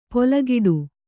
♪ polagiḍu